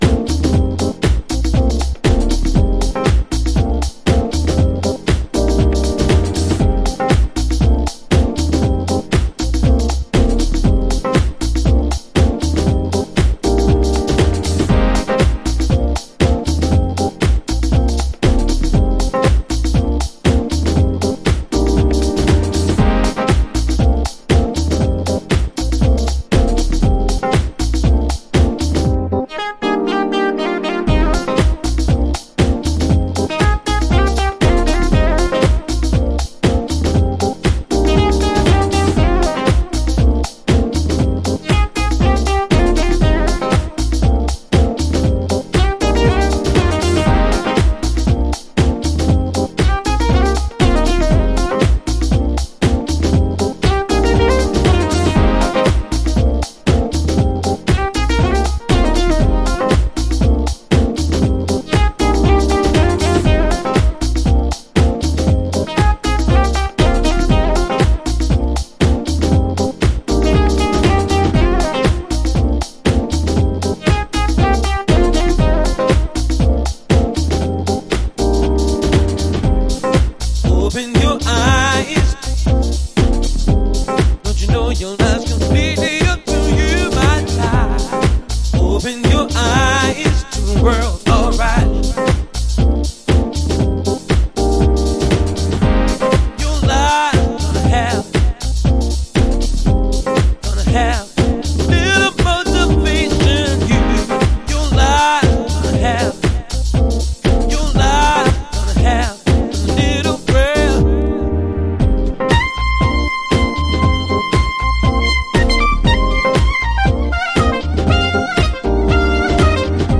暖かいアコースティックと高揚ヴォーカルのハルモニア。
House / Techno